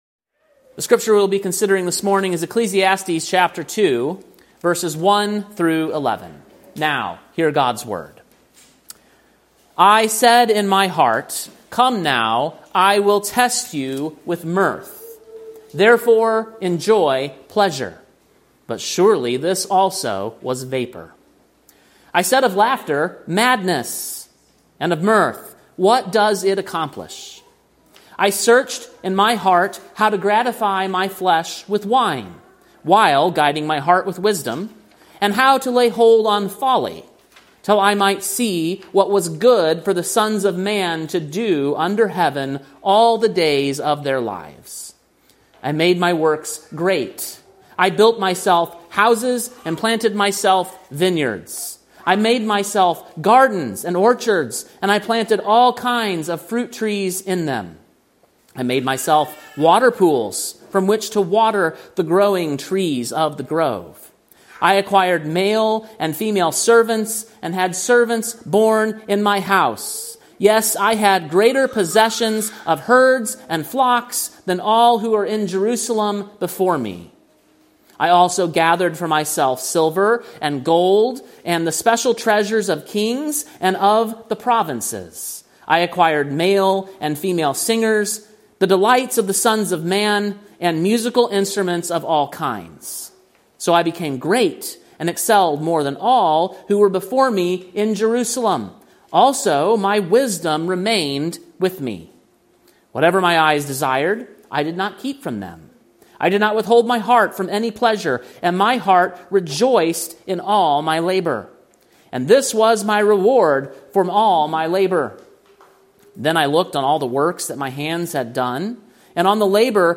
Sermon preached on September 21, 2025, at King’s Cross Reformed, Columbia, TN.